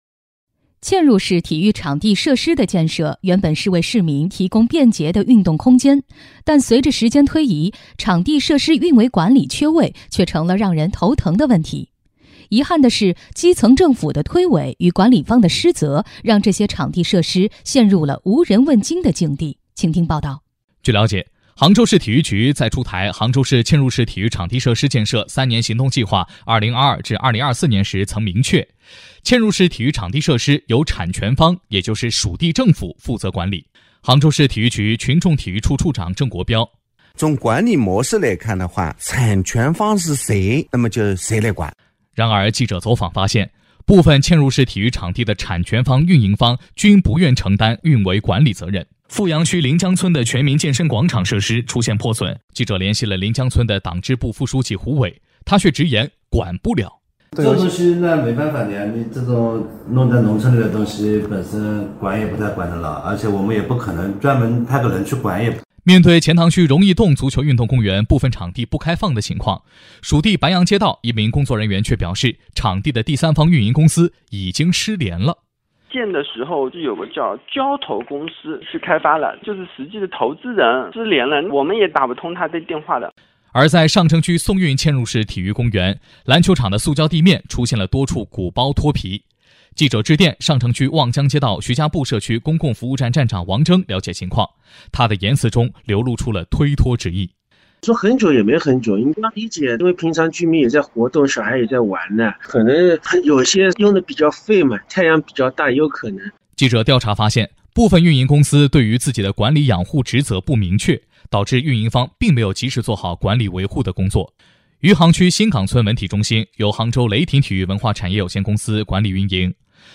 根据相关评奖要求，我单位拟推荐杭州人民广播电台的广播消息（系列报道）《后亚运时代，建好的嵌入式体育场地设施谁来管？》和南京广播电视台的广播专题《“零碳乡村”不等于“穷村”！》参加第四届中国广播电视大奖•中国广播电视节目奖（2024年度）复评，现予公示。